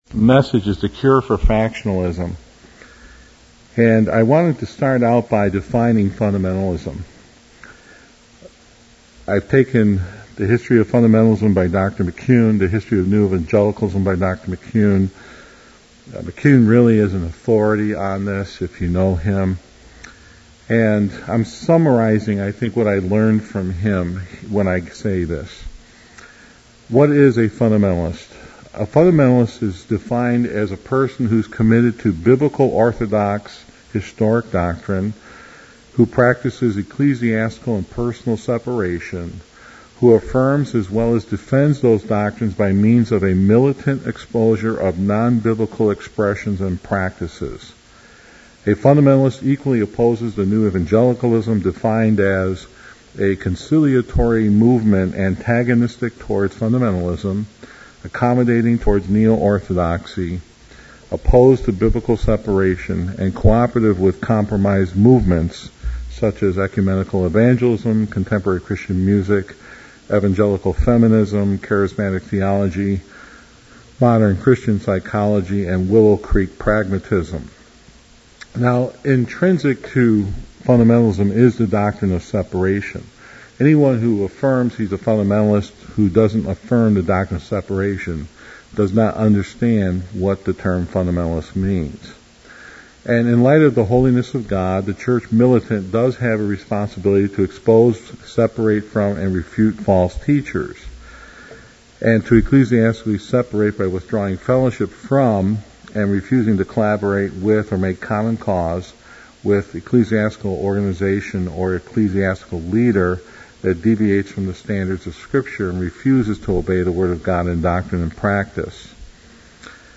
FBFI Northwest Regional Fellowship 1 Cor 3.1-10.